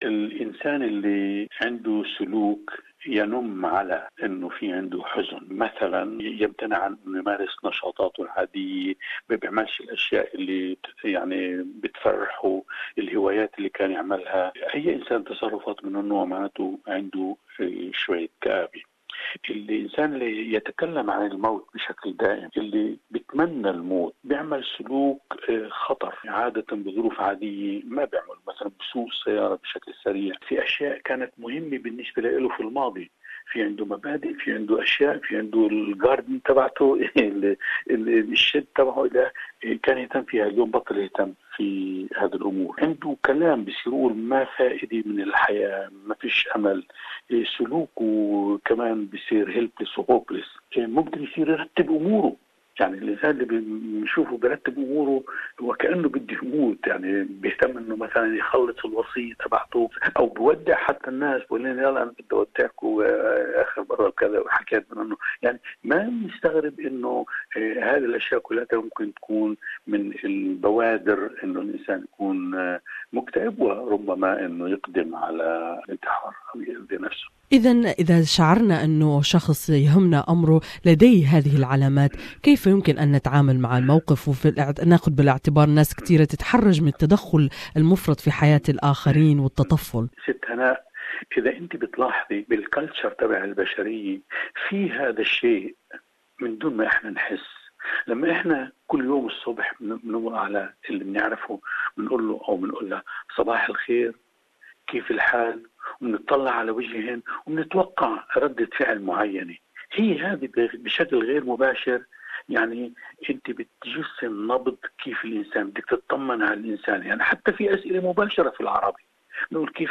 R U OK? Day is an annual day in September (the second Thursday) dedicated to remind people to ask family, friends and colleagues the question, "R U OK?", in a meaningful way, because connecting regularly and meaningfully is one thing everyone can do to make a difference to anyone who might be struggling. More in this interview with Psychologist